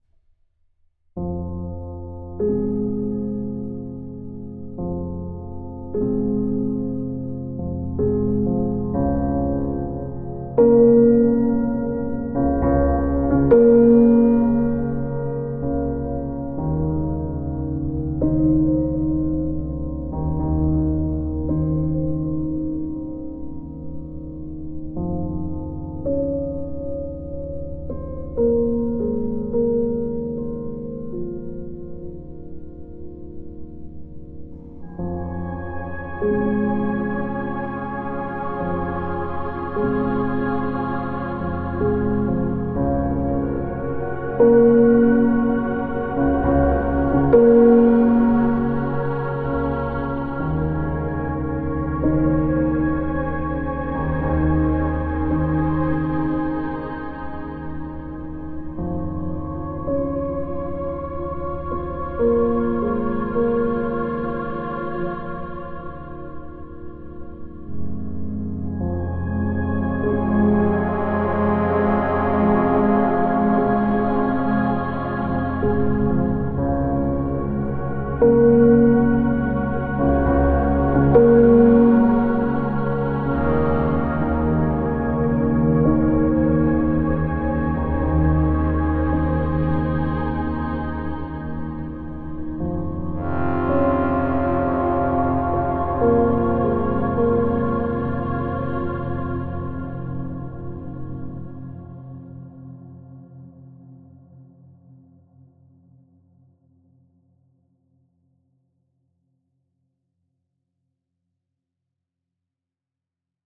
Testing out a new vocal synth (FOLDS) - frequencies a bit strong for mobile